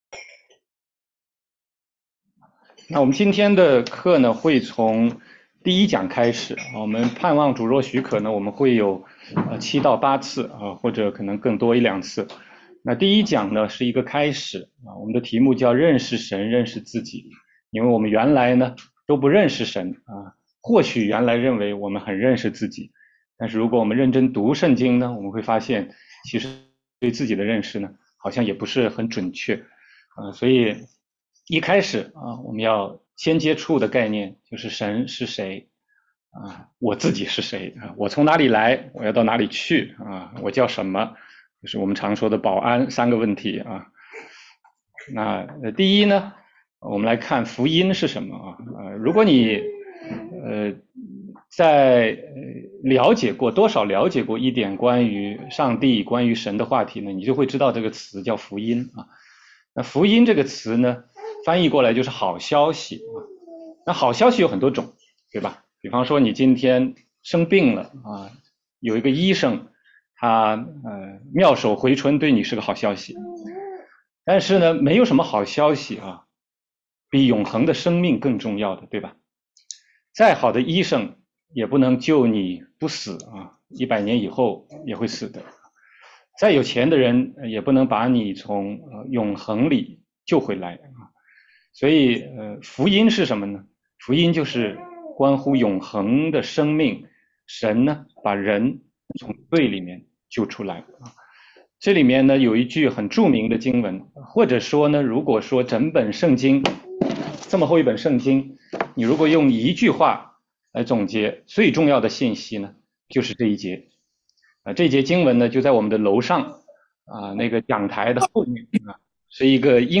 16街讲道录音 - 得救的福音第一讲：认识神，认识自己